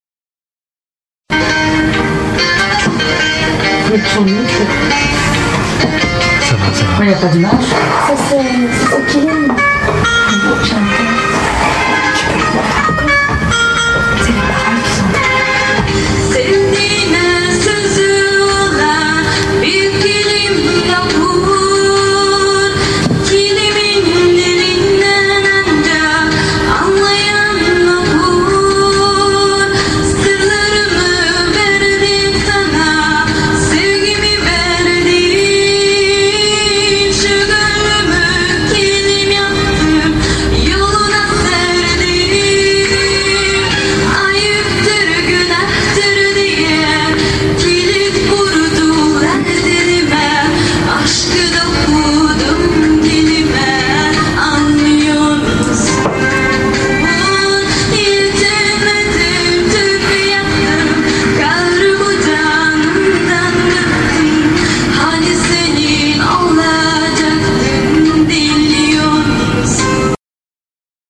anders Une Afghane qui chante sur le Kilim. anders Explication d'un texte chanté en turc.